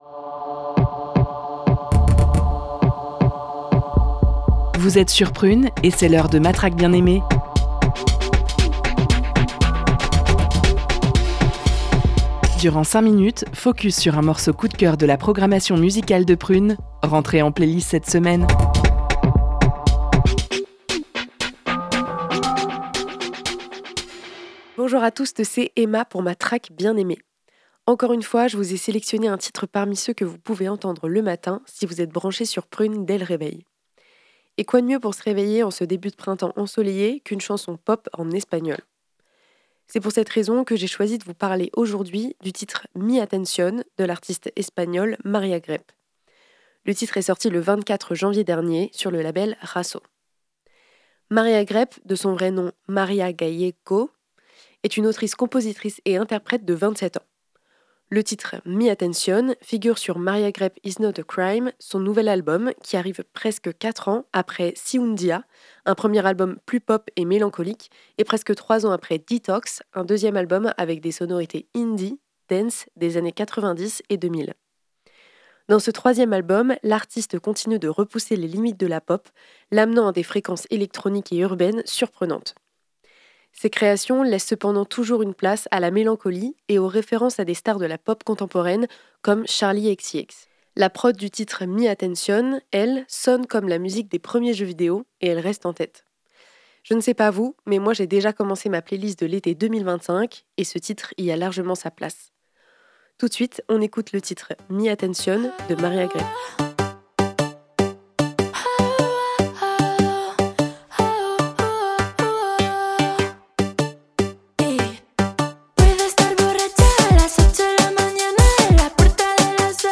sonne comme la musique des premiers jeux vidéos